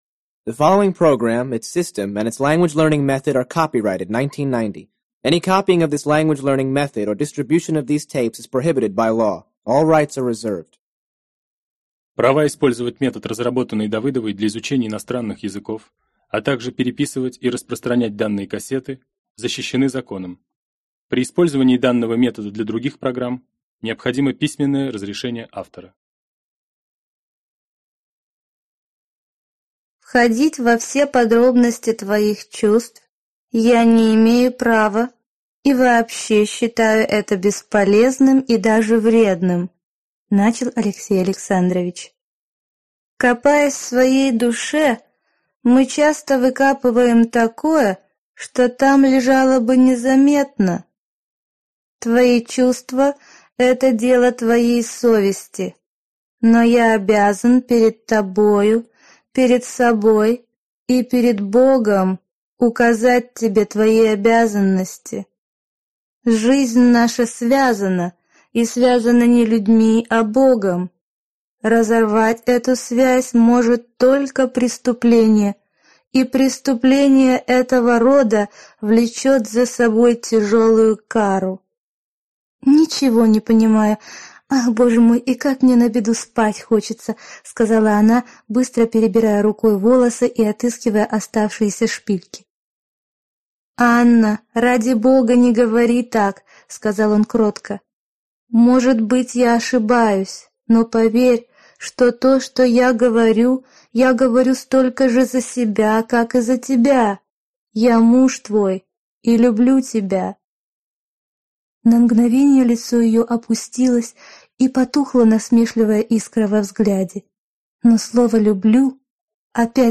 Аудиокнига Сложный литературный английский. Диск 2 | Библиотека аудиокниг